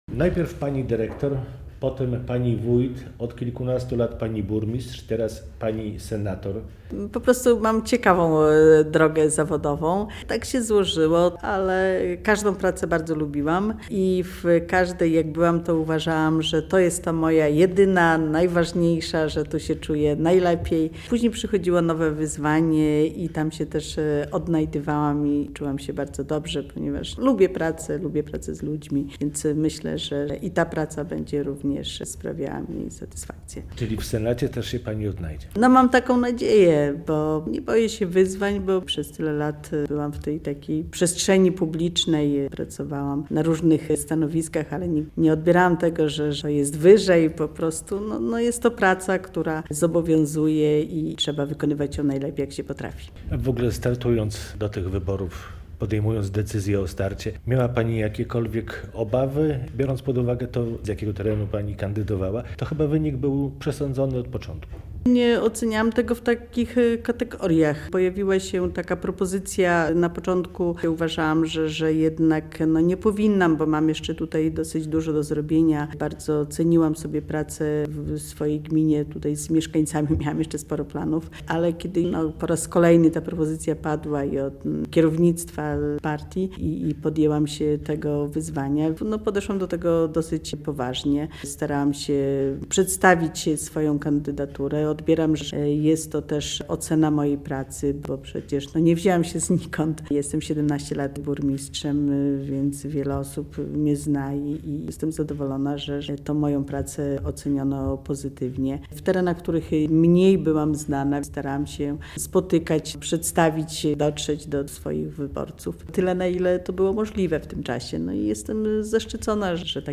Radio Białystok | Gość | Anna Bogucka - nowo wybrana senatorka